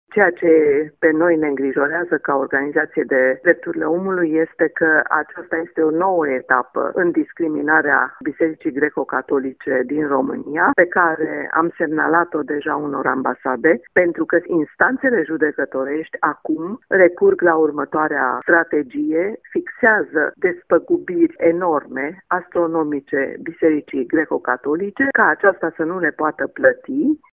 Mai multe ambasade au fost înștiințate de acest caz, spune președintele Ligii Pro Europa, Smaranda Enache: